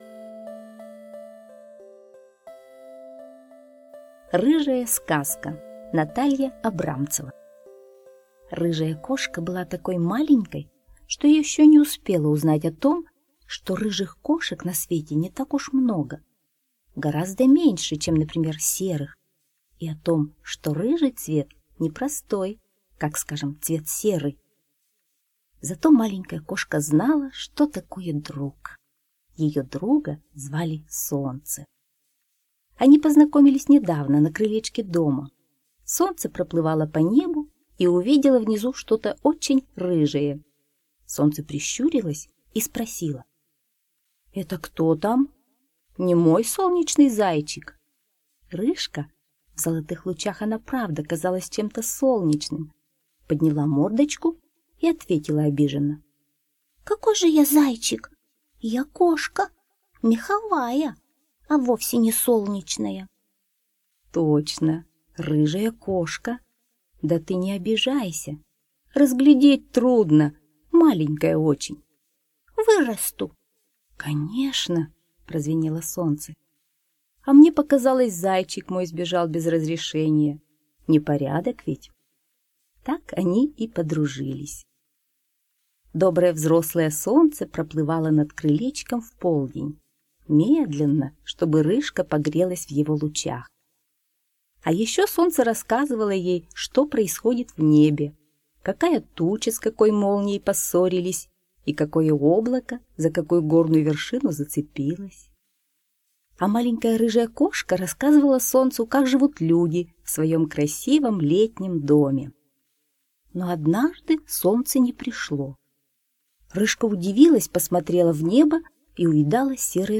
Рыжая сказка - аудиосказка Абрамцевой Н. Сказка про рыжего котенка и его друга солнце. Однажды солнце закрыли тучи и котенок пошел его искать.